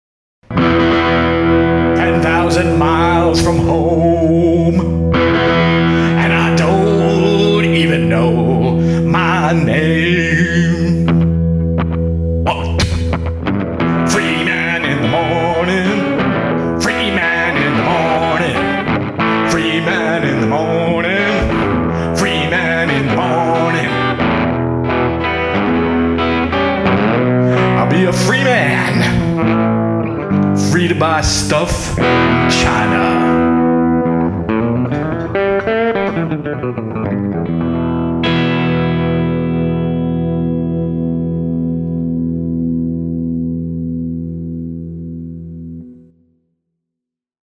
And here, another fitting musical one-minute interlude from US of Fail:
It’s the live intro to China Toilet Blooz which you should click up for me on YouTube.